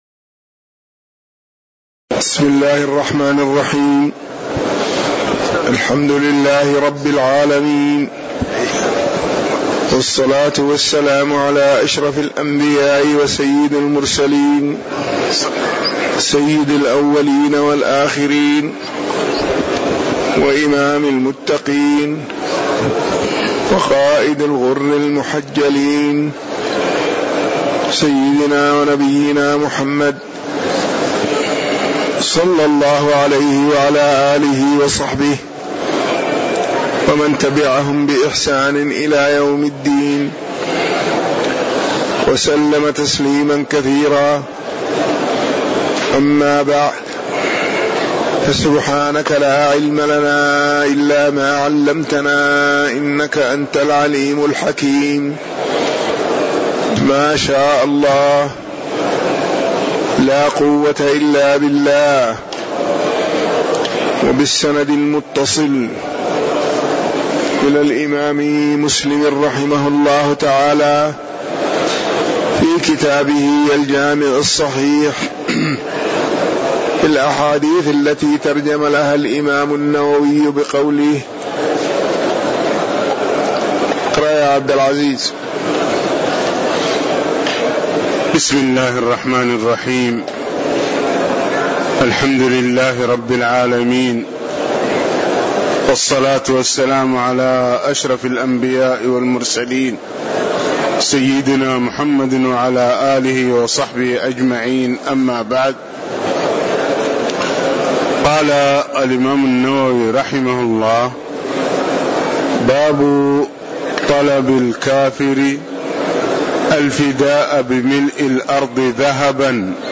تاريخ النشر ٩ جمادى الآخرة ١٤٣٨ هـ المكان: المسجد النبوي الشيخ